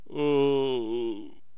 shaman_die3.wav